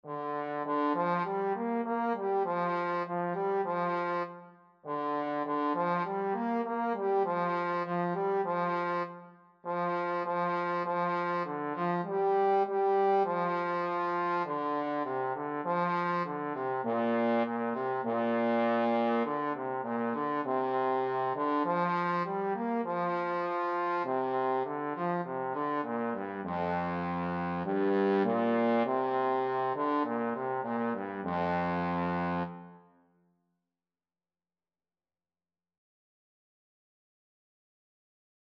Free Sheet music for Trombone
A popular Chinese folk song, dating back to the 18th century.
Bb major (Sounding Pitch) (View more Bb major Music for Trombone )
2/2 (View more 2/2 Music)
F3-Bb4
Trombone  (View more Easy Trombone Music)
Traditional (View more Traditional Trombone Music)